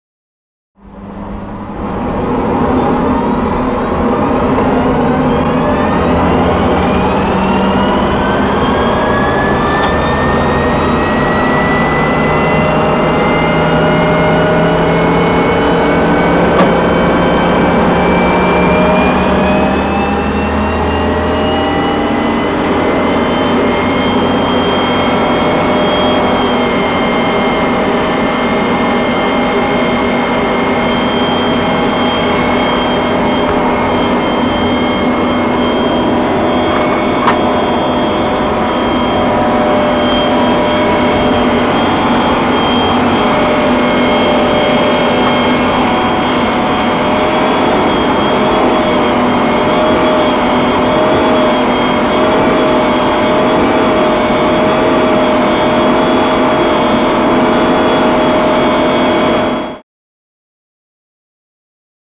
Helicopter; Interior; Wessex Mk5 Helicopter Interior Start Up, Motor Idling And Take Off To Constant Flying.